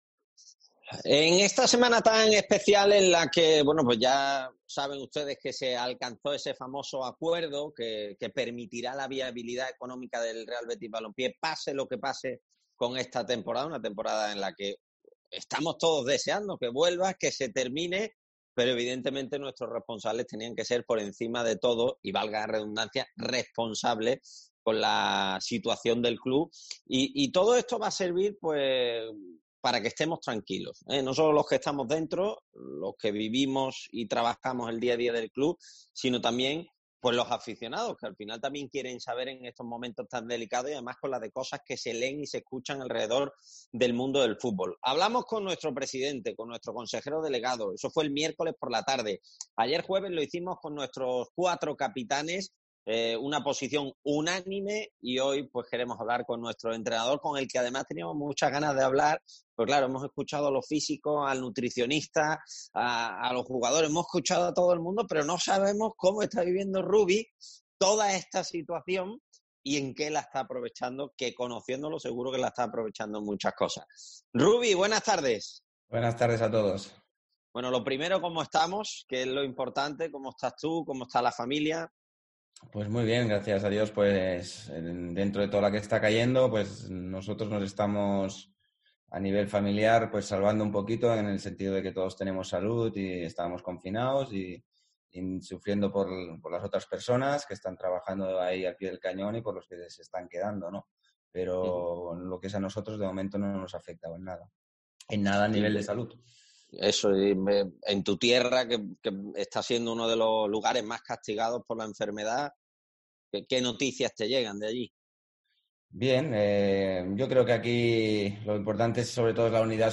Rubi, entrenador del Real Betis, habla en los medios del club